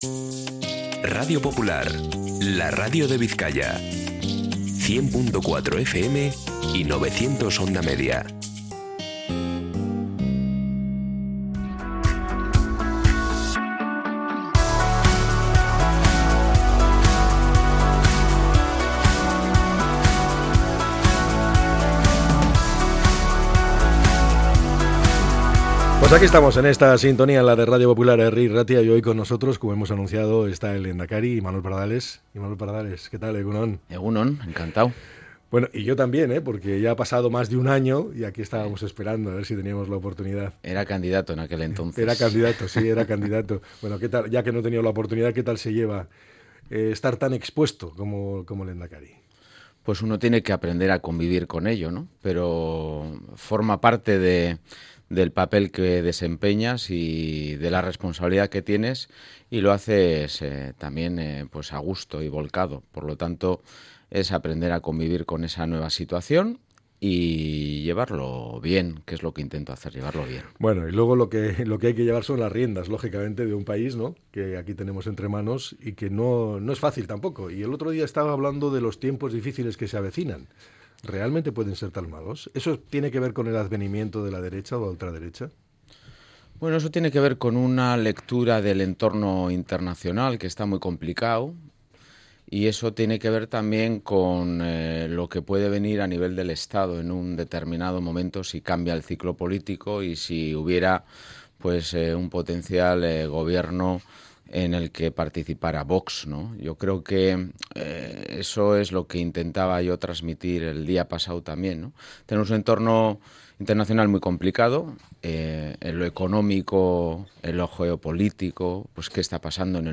El lehendakari ha repasado la actualidad política en una extensa entrevista